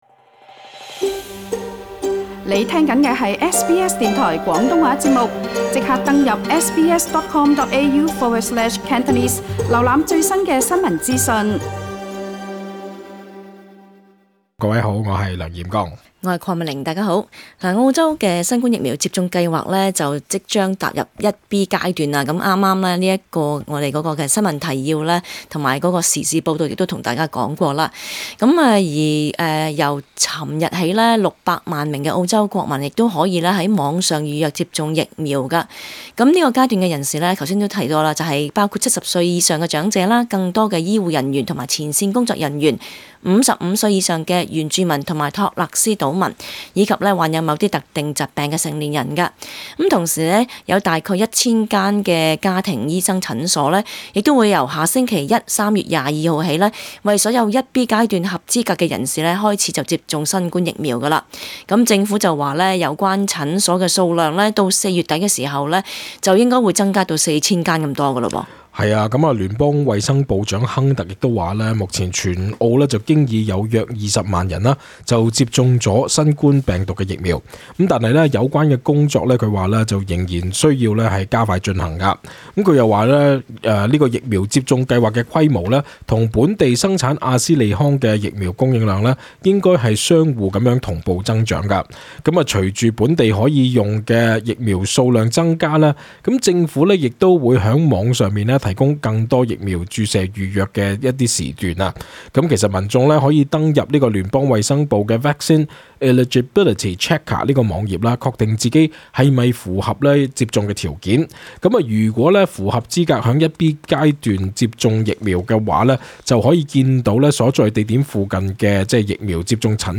本節目內嘉賓及聽眾意見並不代表本台立場 在澳洲，所有人必須保持至少 1.5 米的社交距離。